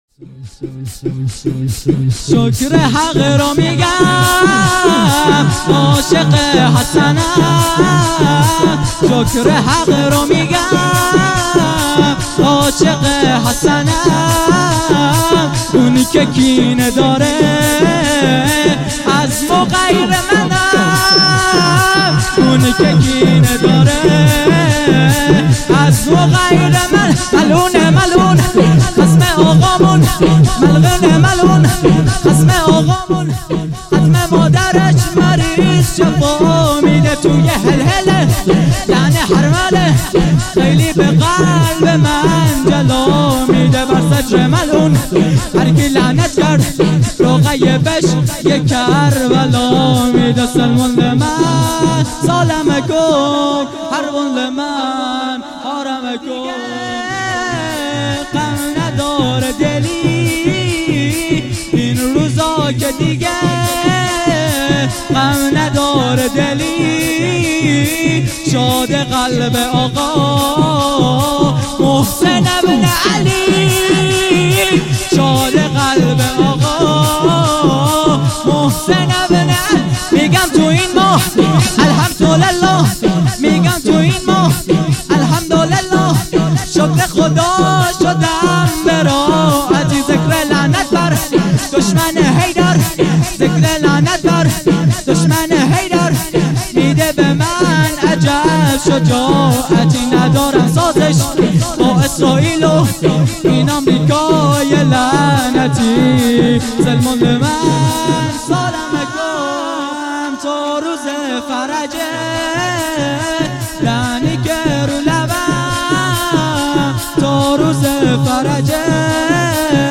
شور
جشن ایام ربیع الاول،97.9.1